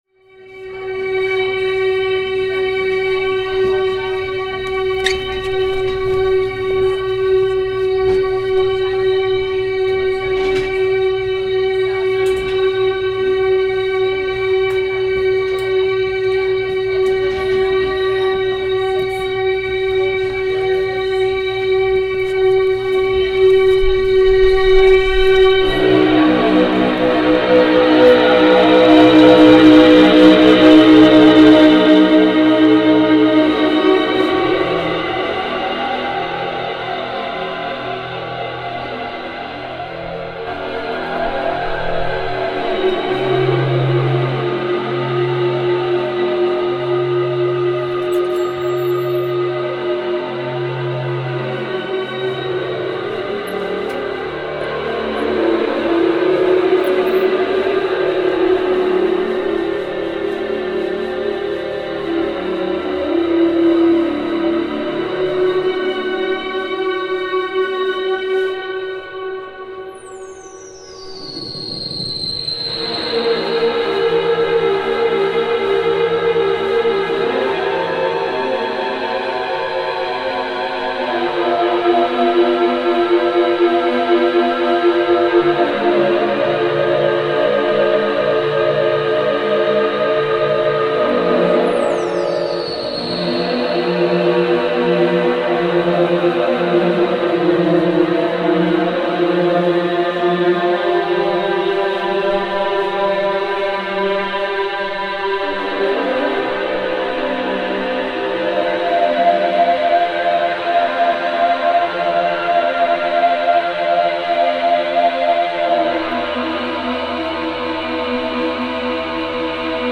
performance at Spotty Dog Books & Ale.
live show...